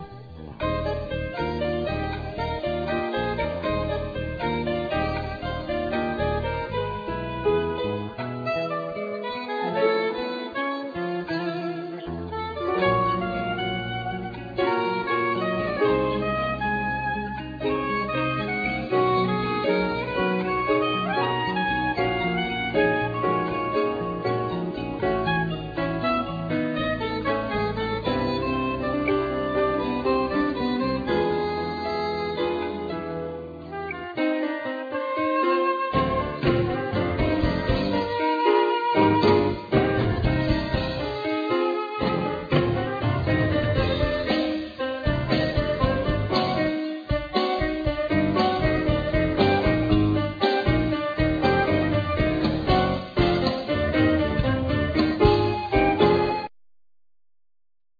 Bass,Ac.guitar,Percussions,Vocal
Synthesizer,Percussions,Vocal
Flute,Percussions,Sopranosaxophne
Drums
Piano